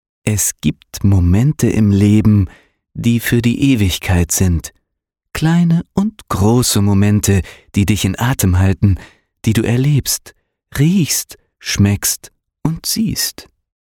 Meine Stimme klingt warm, präzise, jung, klar und facettenreich.
Sprechprobe: eLearning (Muttersprache):
Young age warm sounding, friendly and fresh voice.